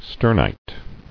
[ster·nite]